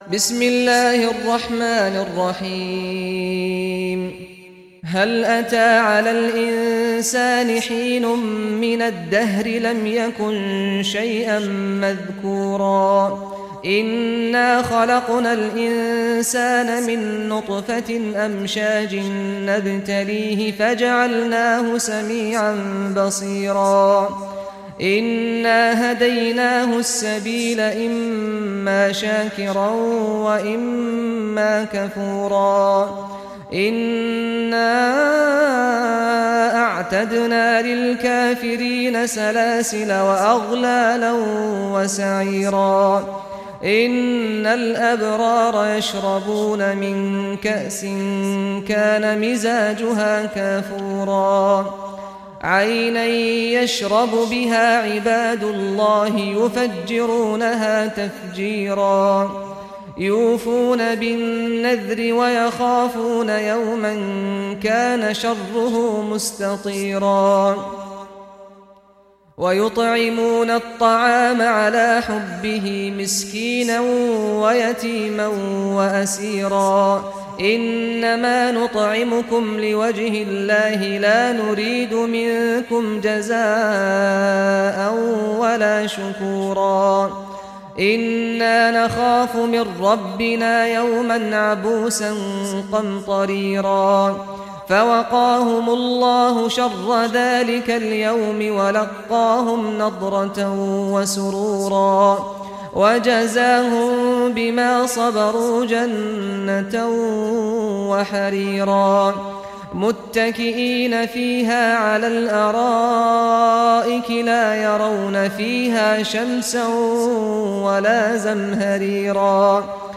Surah Al-Insan Recitation by Sheikh Saad al Ghamdi
Surah Al-Insan, listen or play online mp3 tilawat / recitation in Arabic in the beautiful voice of Sheikh Saad al Ghamdi.